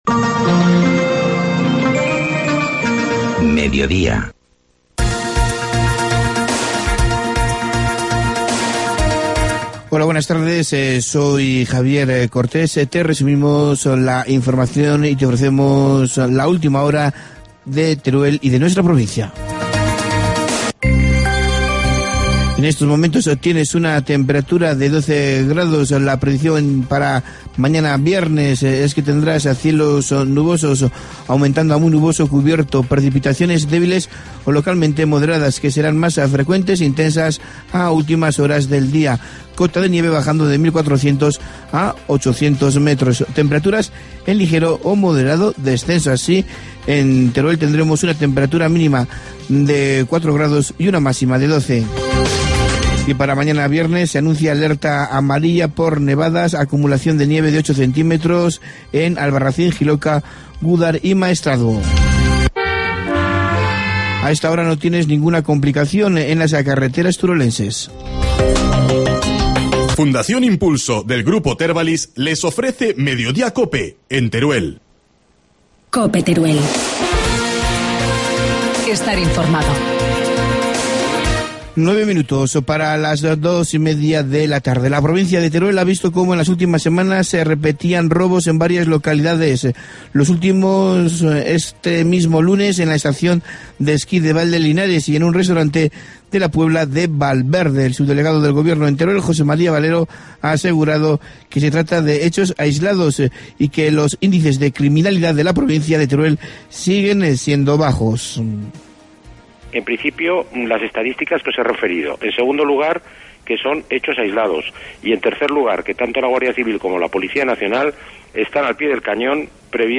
Informativo mediodía, jueves 21 de febrero